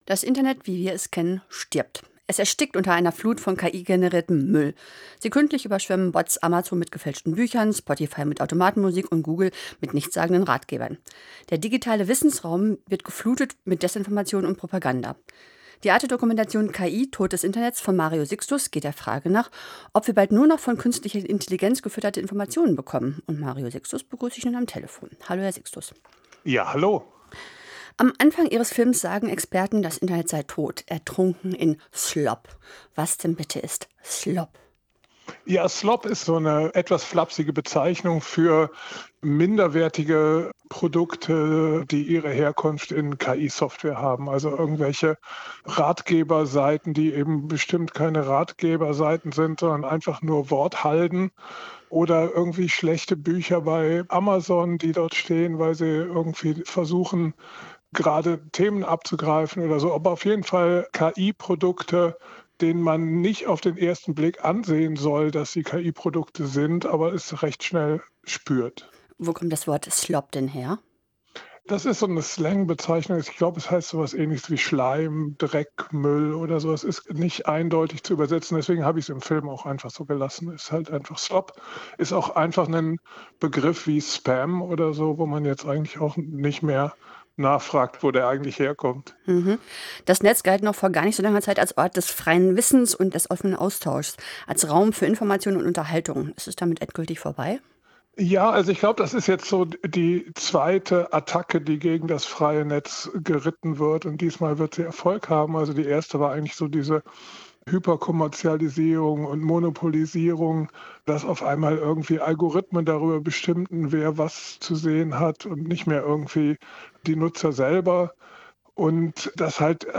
INTERVIEW
InterviewKI.mp3